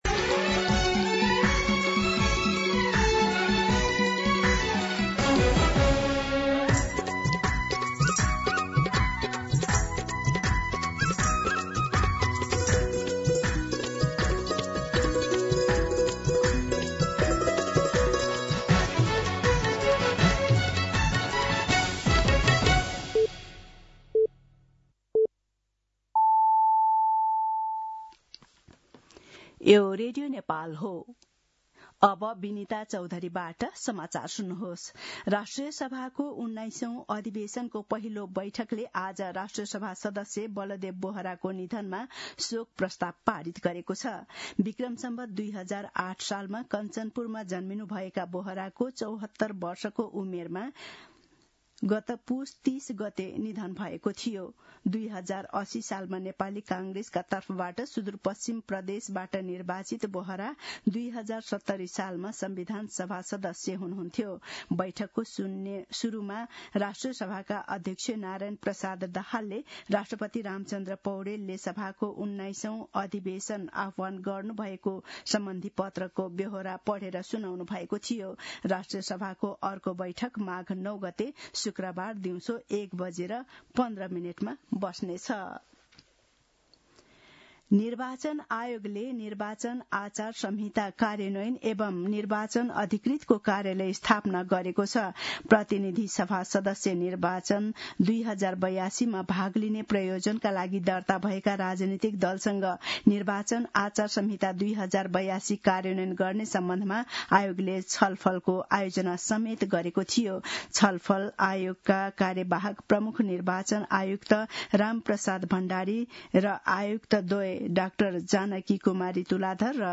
मध्यान्ह १२ बजेको नेपाली समाचार : ४ माघ , २०८२